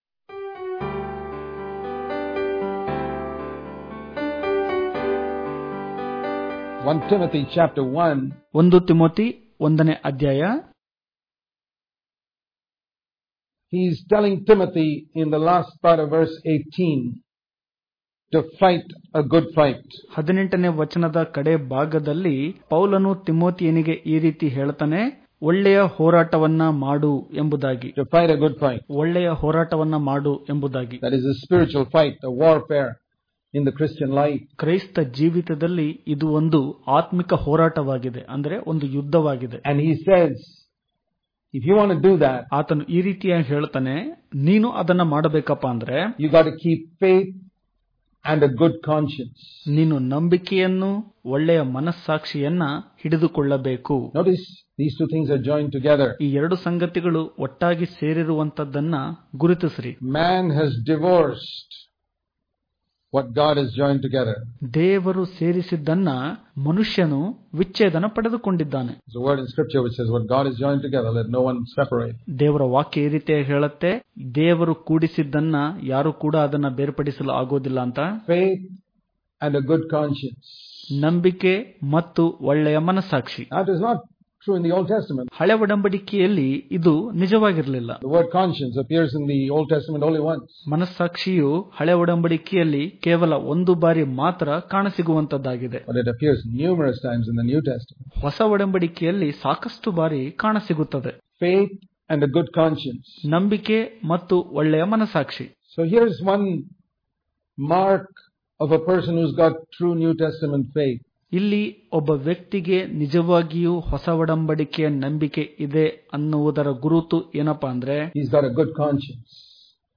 October 21 | Kannada Daily Devotion | Faith And Good Conscience Daily Devotions